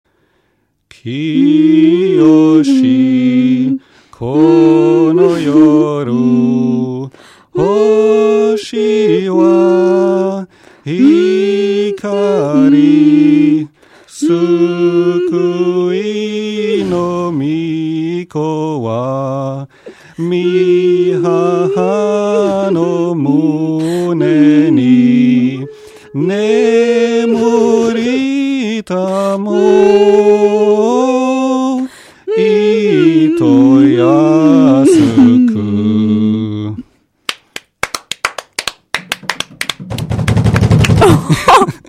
weihnachtslied.mp3